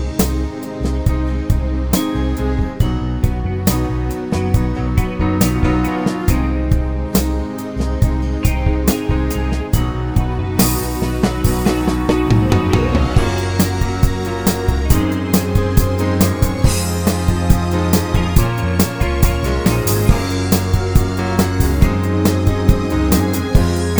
No Lead Backing Vocals At End Soul / Motown 3:33 Buy £1.50